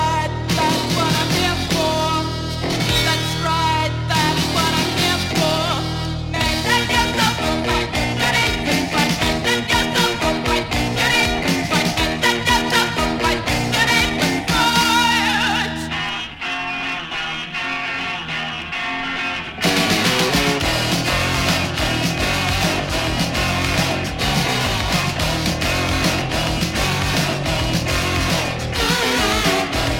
saxofone
Som claramente - diríamos até orgulhosamente - datado